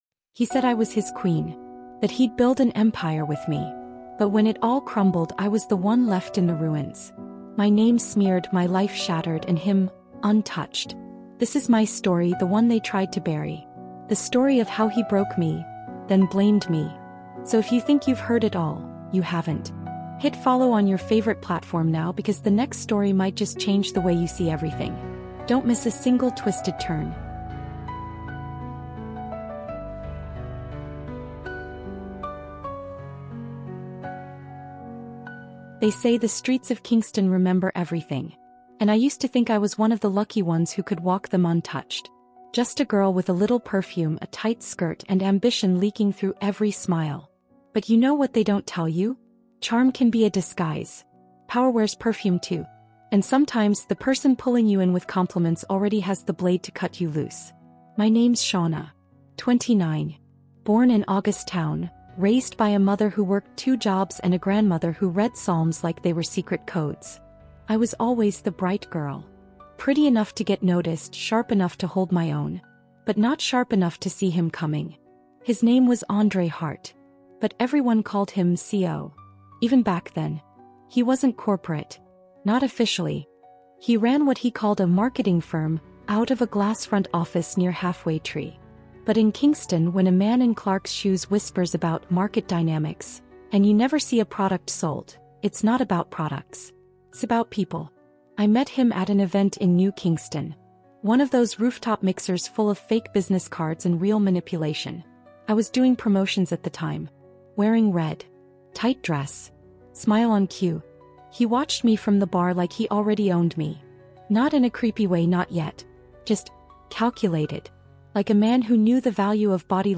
In the gritty streets of Kingston, Jamaica, one woman’s voice cuts through the silence of emotional abuse, gaslighting, and psychological warfare. MANIPULATION: The Woman He Broke, Then Blamed is a raw, first-person true crime-inspired psychological thriller about power, control, and the dangerous art of erasing someone without ever lifting a finger. Told over six emotionally immersive chapters, this gripping audio experience explores the tactics of manipulation, the psychology of influence, and the weaponization of communication.